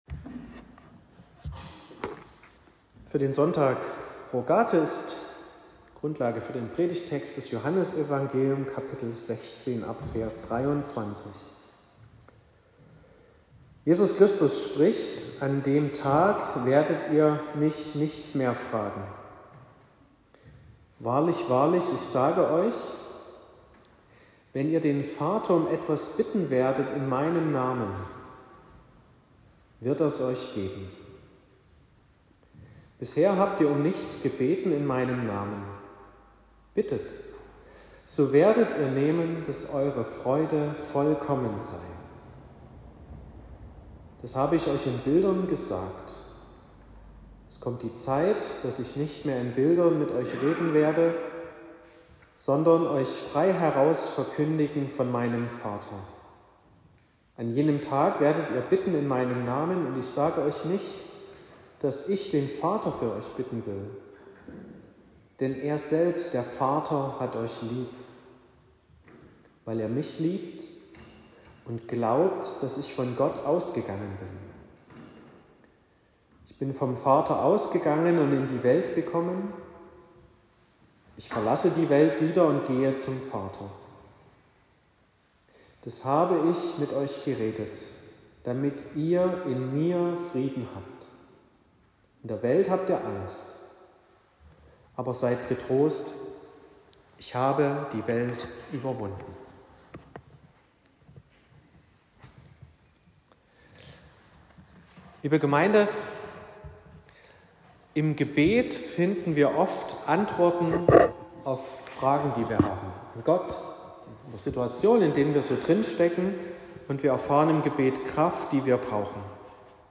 25.05.2025 – Gottesdienst
Predigt (Audio): 2025-05-25_Friede__Freude__Vaterliebe.m4a (10,5 MB)